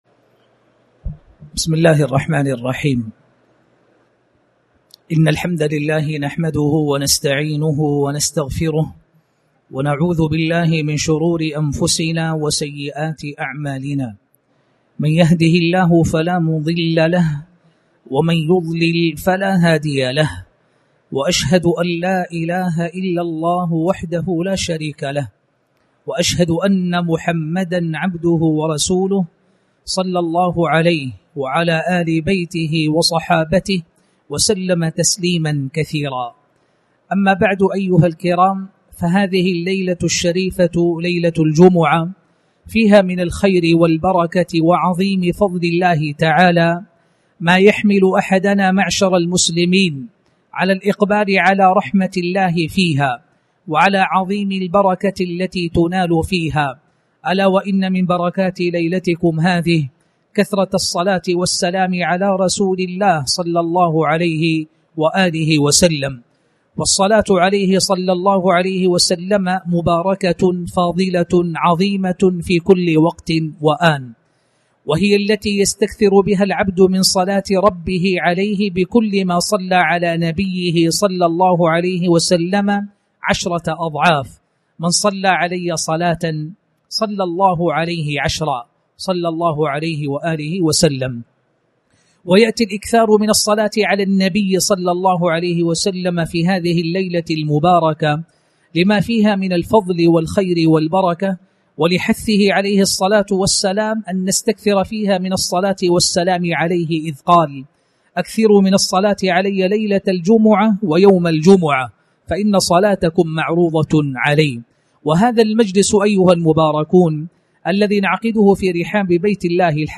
تاريخ النشر ١٩ ربيع الأول ١٤٣٩ هـ المكان: المسجد الحرام الشيخ